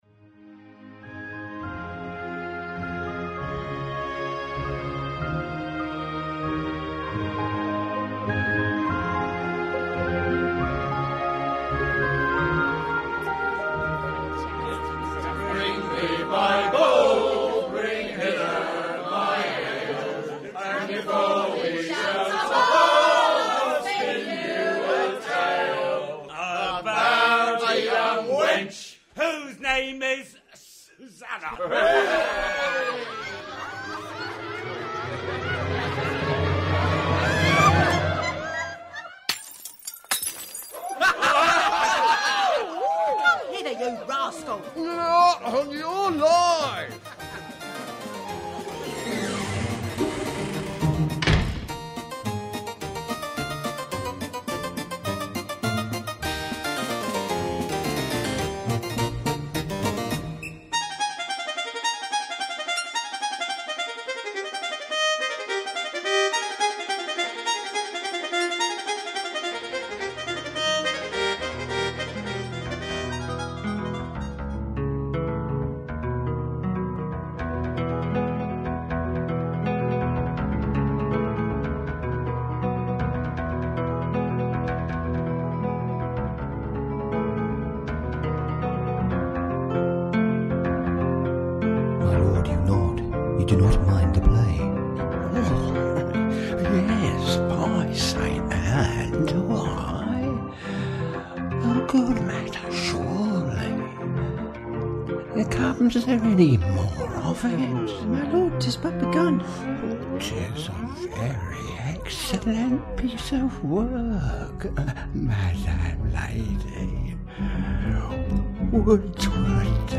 Orchestral & Instrumental Composer
As sound designer and composer I had enormous fun on this popular Shakespeare comedy, poking fun at several genres and remaining on the wall some of the time but not afraid of going off the wall on other occasions.